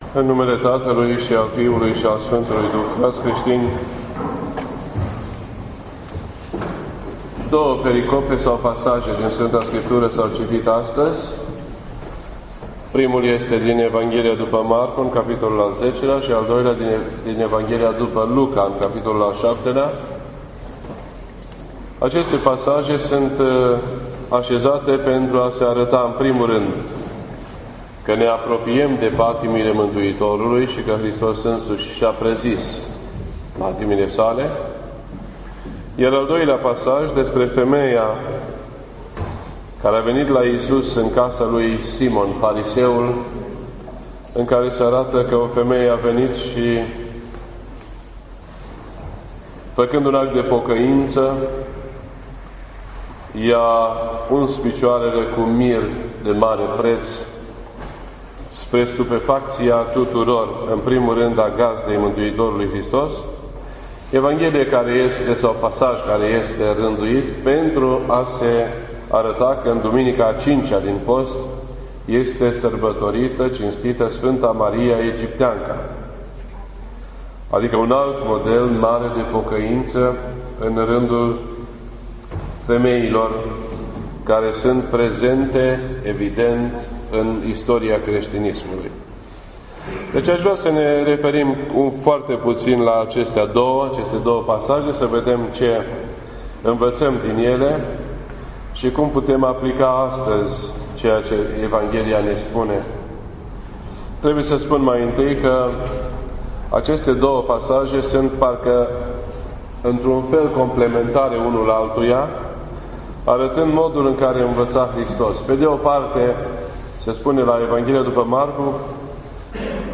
This entry was posted on Sunday, April 17th, 2016 at 10:45 AM and is filed under Predici ortodoxe in format audio.